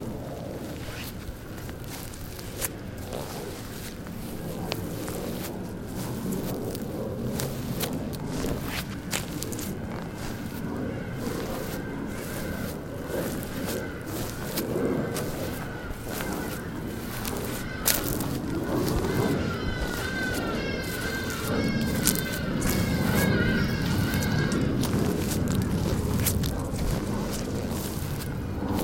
脚步草1
描述：Foley的人走在草丛中
Tag: 脚步 台阶 台阶 走路 行走